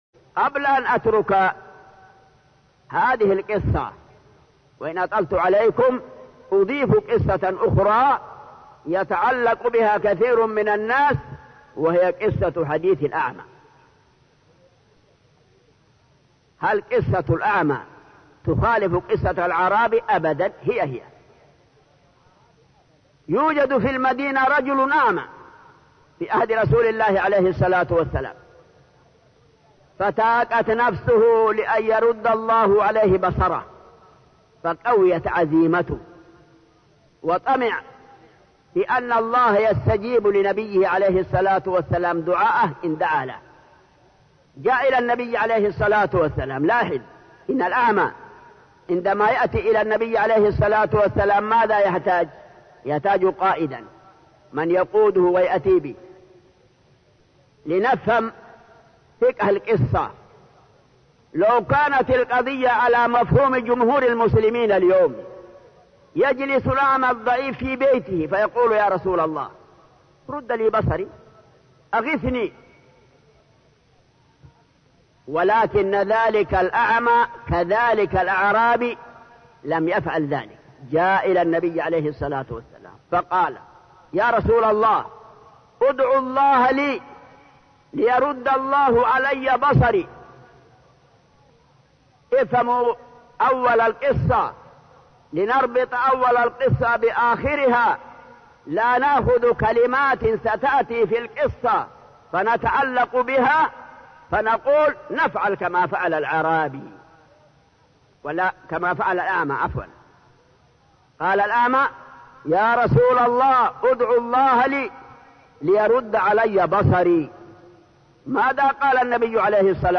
Format: MP3 Mono 22kHz 64Kbps (CBR)